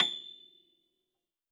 53r-pno25-A5.aif